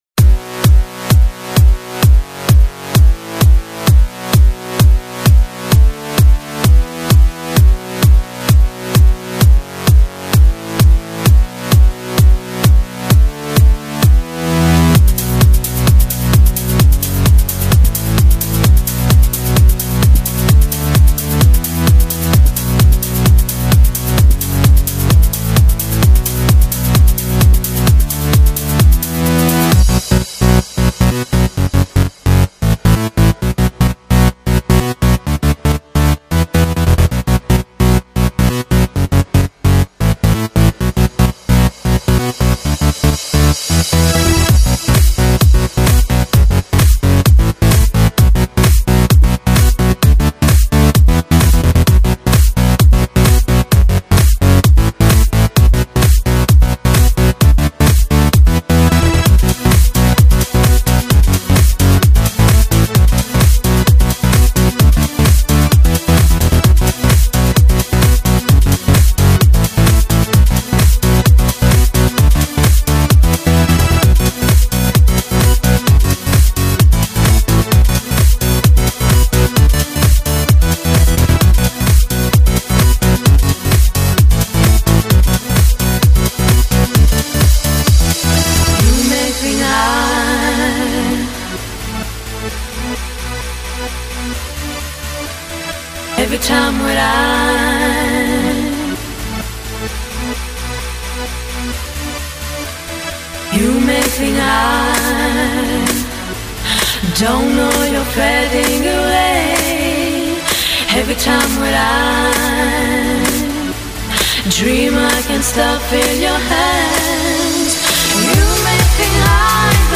Жанр:Electro/House